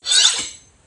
sword_pickup.wav